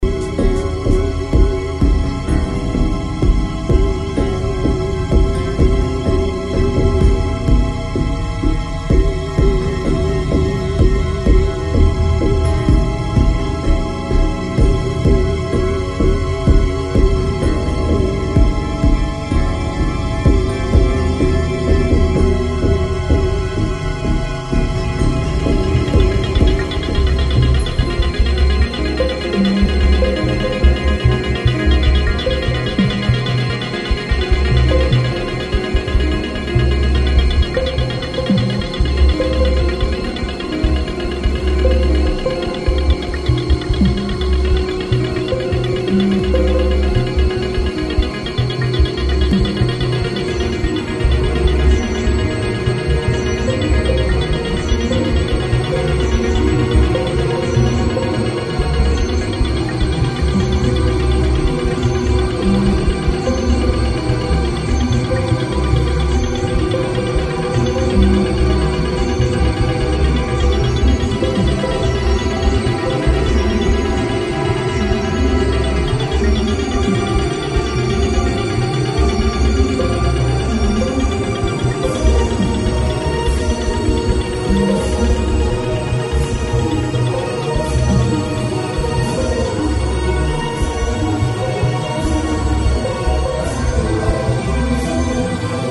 As an electronic symphonic music creation